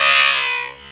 goblin_dies.ogg